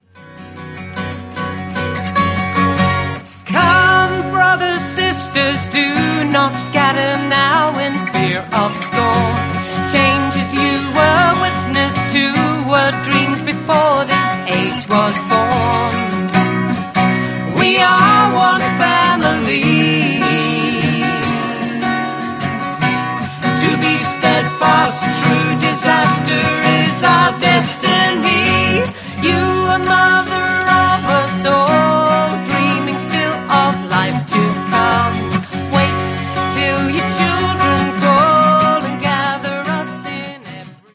gave it a bit of Bouzouki Rock and some harmonies